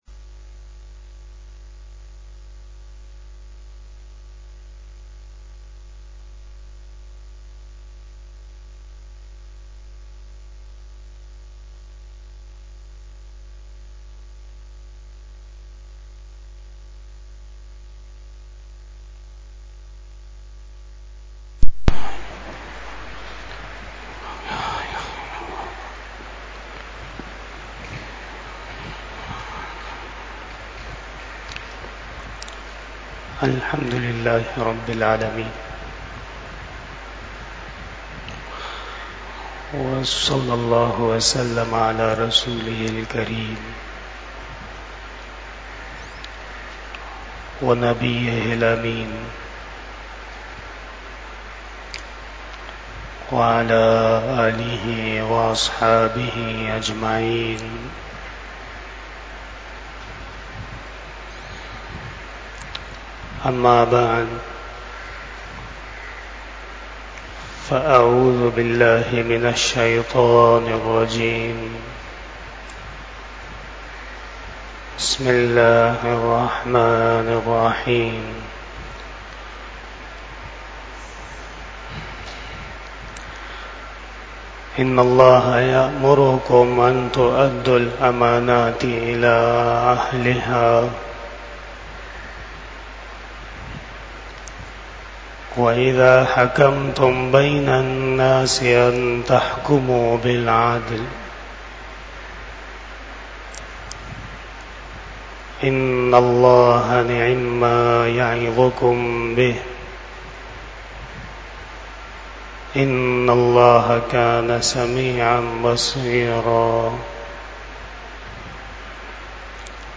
30 Bayan E Jummah 26 July 2024 (19 Muharram 1446 HJ)